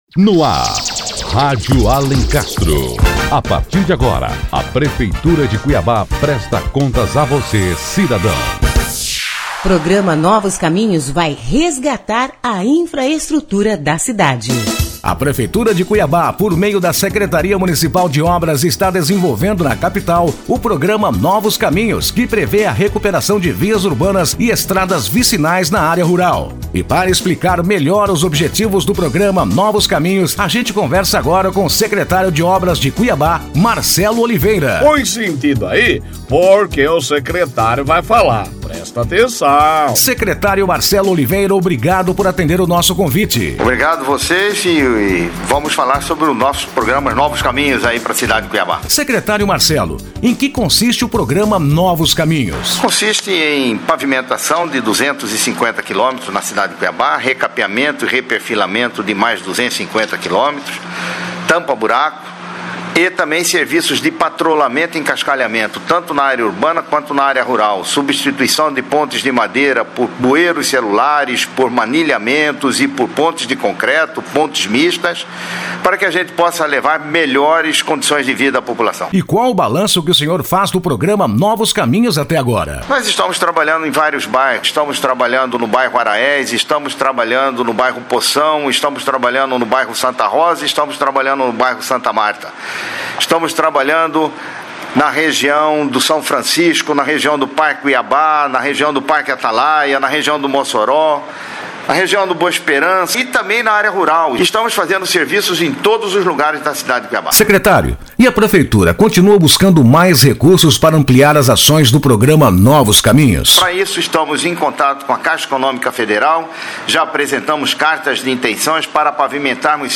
Ouça a entrevista com o Secretário Municipal de Obras, Marcelo Oliveira, e saiba mais sobre este programa da Prefeitura de...